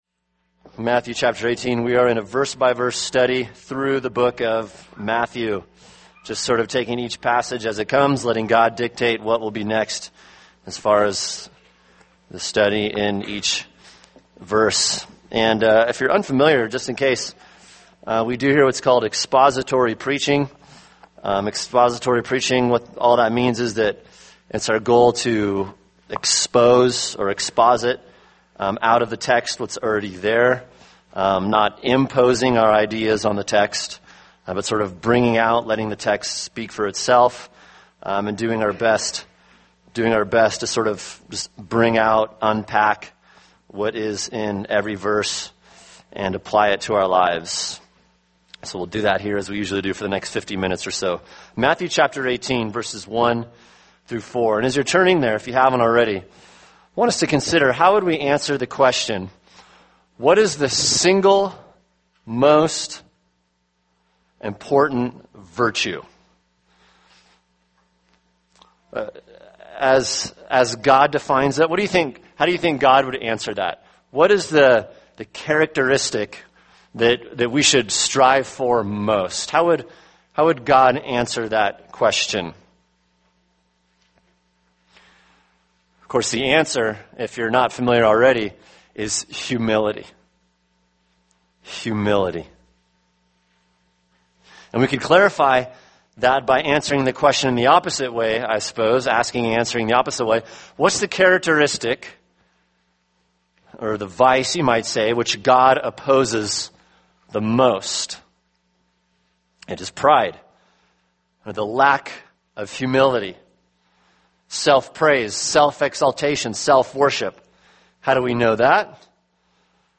[sermon] Matthew 18:1-4 – The Greatness of Humility | Cornerstone Church - Jackson Hole